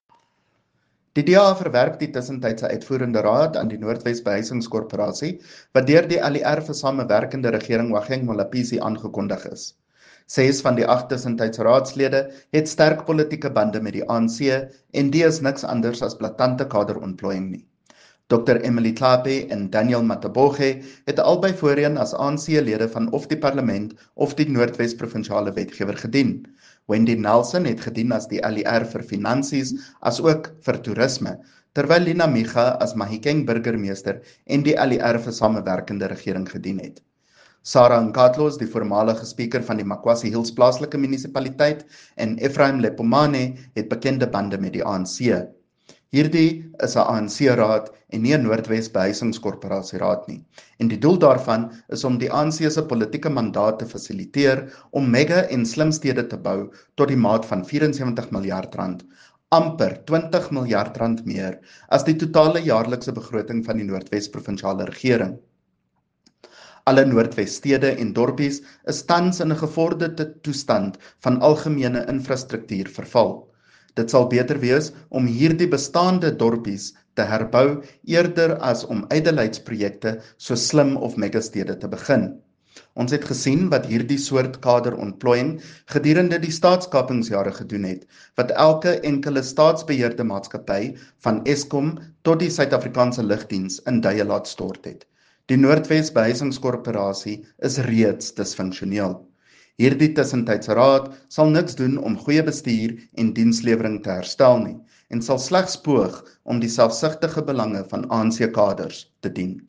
Note to Broadcasters: Please find linked soundbites in
Afrikaans by CJ Steyl MPL.